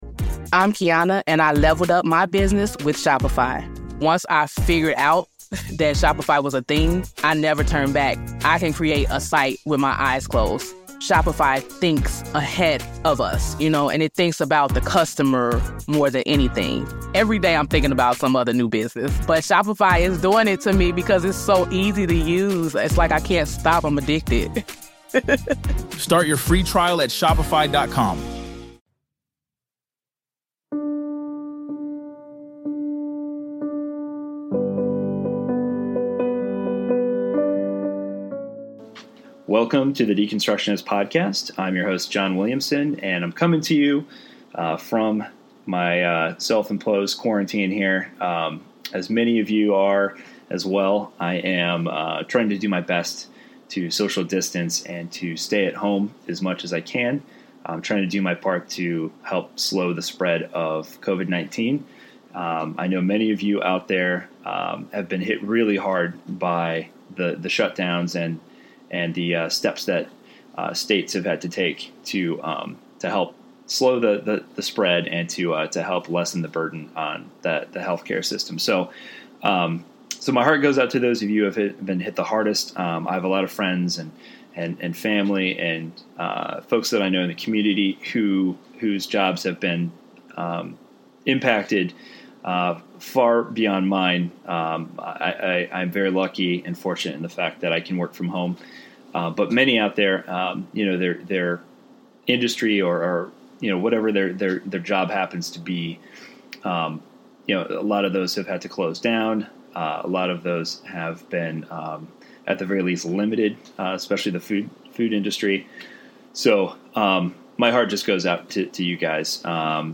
This is the first of a series of sermons we recorded a few years ago that would thought we’d share...
Sunday Sermon Series: The Temptation of Christ